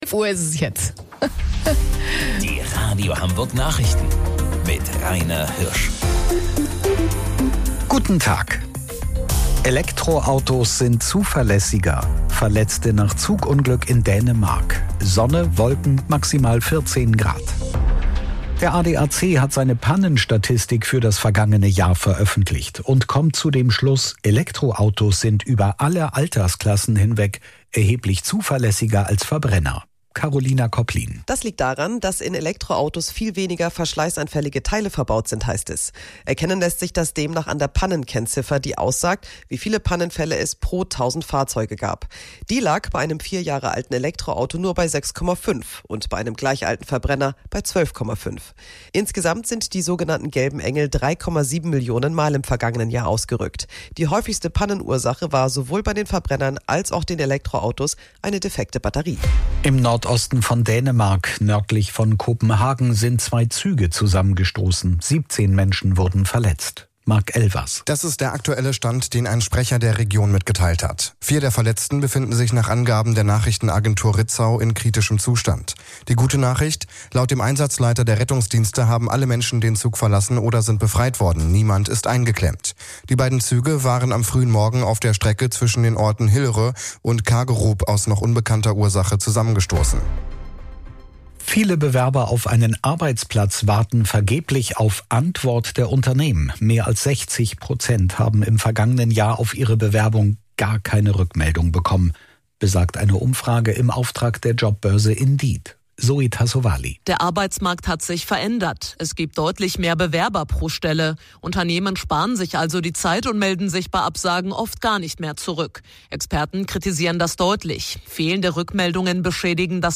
Radio Hamburg Nachrichten vom 23.04.2026 um 11 Uhr